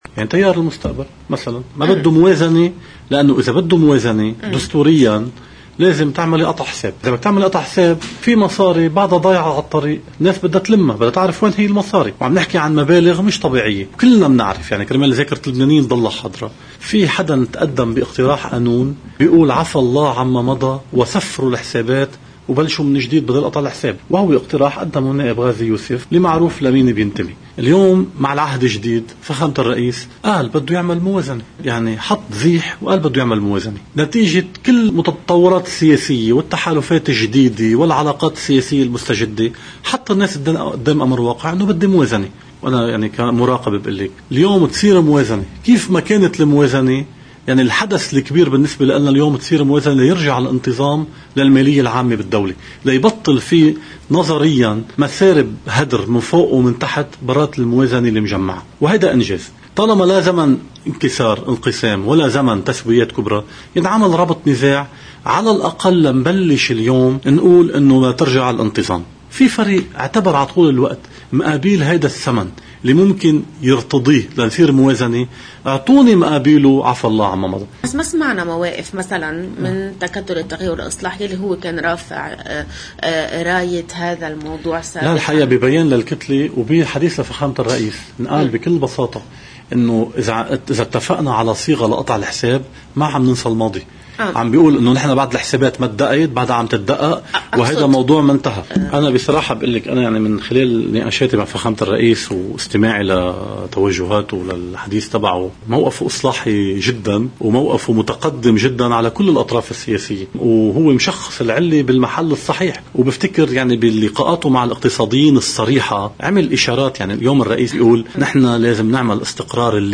مقتطف من حديث الخبير الاقتصادي